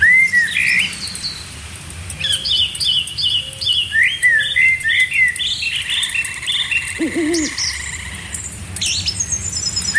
Bruitage – Feu de camp – Le Studio JeeeP Prod
Bruitage haute qualité créé au Studio.
Feu-de-camp.mp3